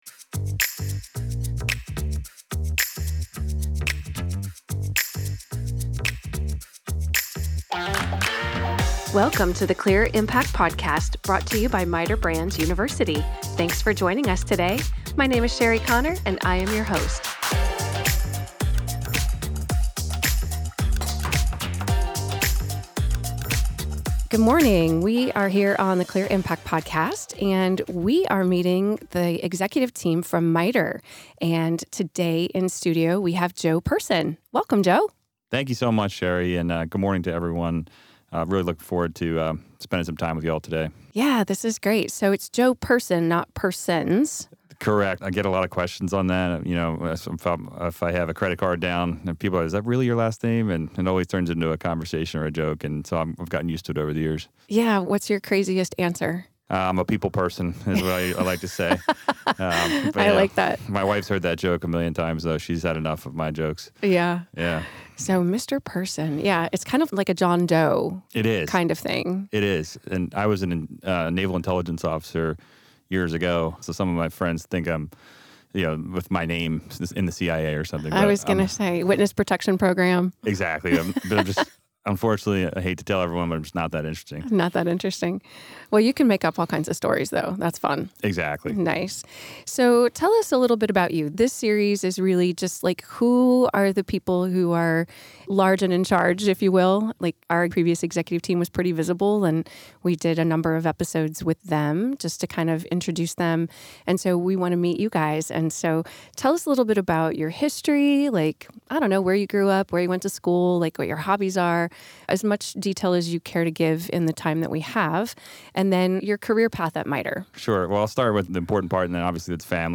The great energy from our conversation really comes through - enjoy!